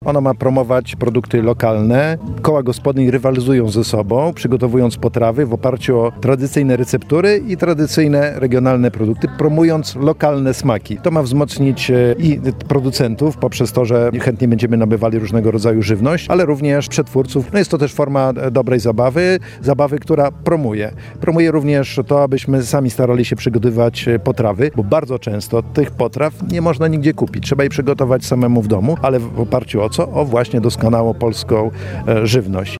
– mówi Henryk Smolarz, dyrektor generalny Krajowego Ośrodka Wsparcia Rolnictwa.